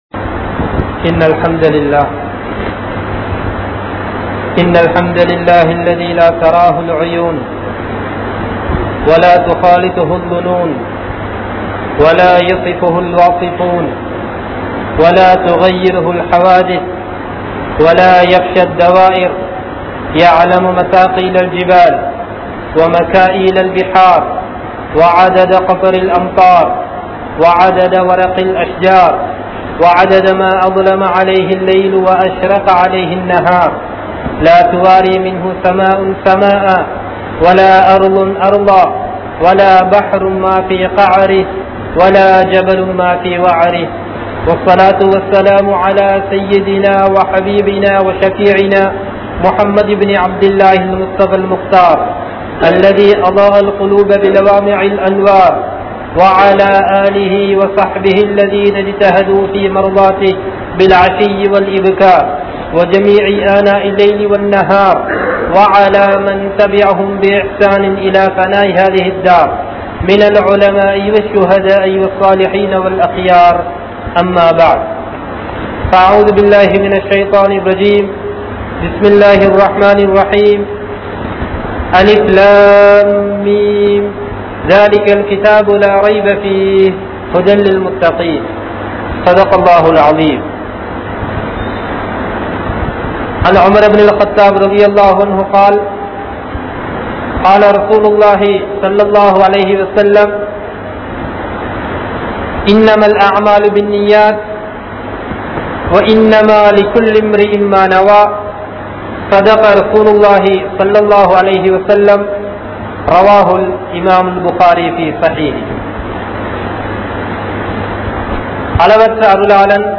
Allah`vukku Nantri Sealuthungal (அல்லாஹ்வுக்கு நன்றி செலுத்துங்கள்) | Audio Bayans | All Ceylon Muslim Youth Community | Addalaichenai
Meera Sahib Jumua Masjith